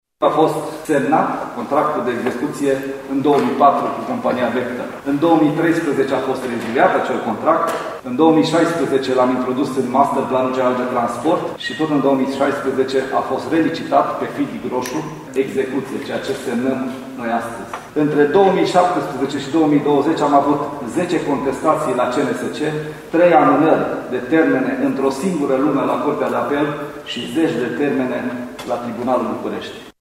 Ministrul Transporturilor, Lucian Bode a declarat astăzi la Târgu Mureș că 2020 este și anul Autostrăzii Transilvania, în contextul în care a participat la semnarea contractului pentru “Construcția sectorului de Autostradă Târgu Mureș – Ungheni și Drum de Legătură”.
În cazul porțiunii Târgu Mureș – Ungheni contractul semnat astăzi este al doilea în ultimii ani, a arătat Lucian Bode: